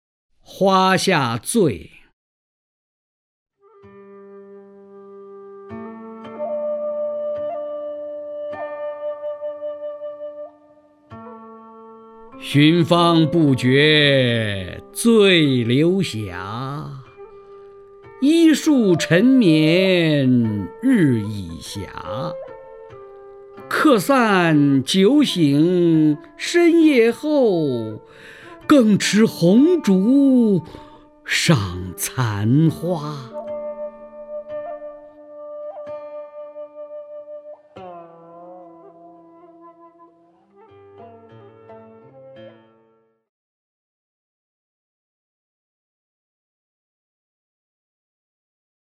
曹灿朗诵：《花下醉》(（唐）李商隐) （唐）李商隐 名家朗诵欣赏曹灿 语文PLUS